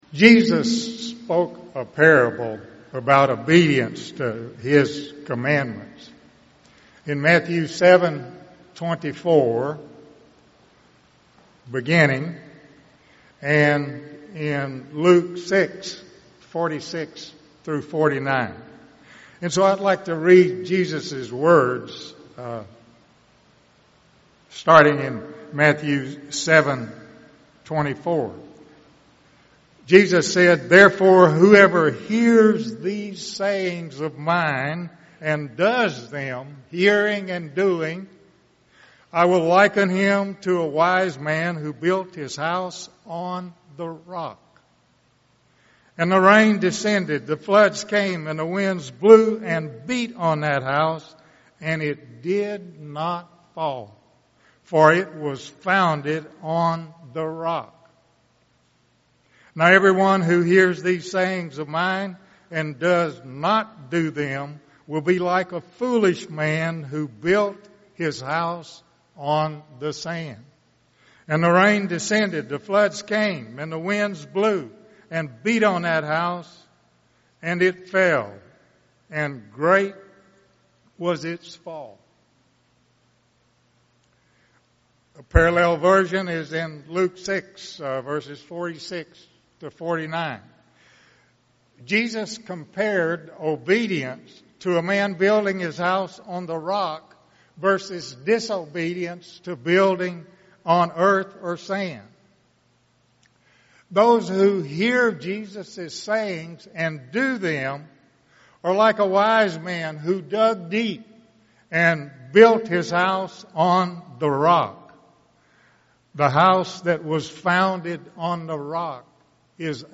Sermons
Given in Tulsa, OK